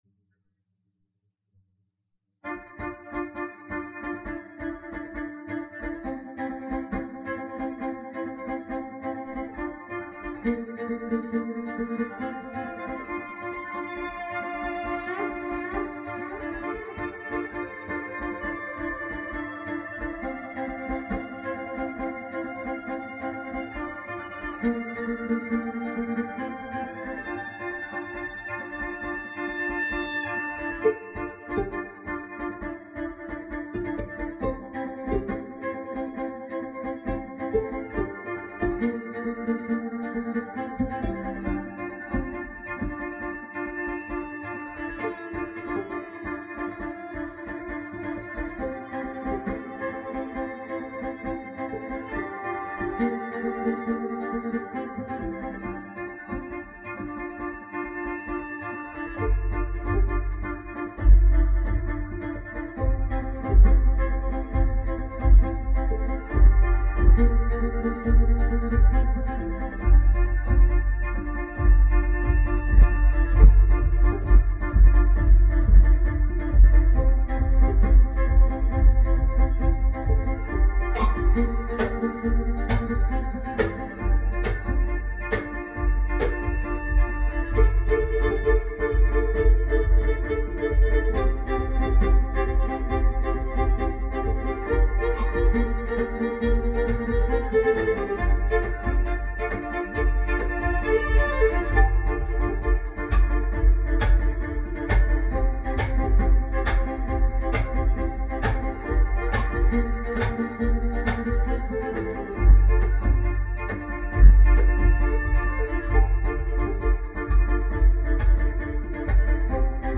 Building Scene